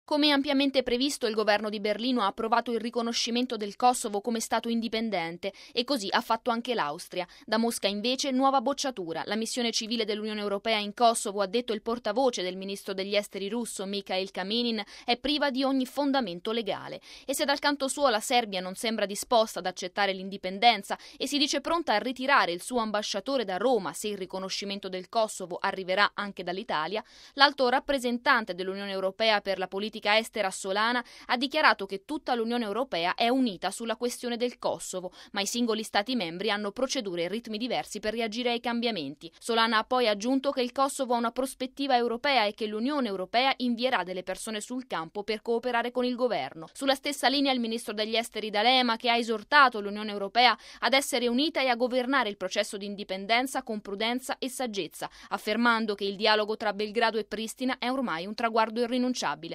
Germania ed Austria hanno riconosciuto ieri il nuovo Stato, mentre in Italia il ministro degli Esteri D’Alema ha riferito la posizione del governo davanti alle Commissioni Esteri di Camera e Senato, definendo utile e necessario stabilire relazioni diplomatiche con Pristina. Il servizio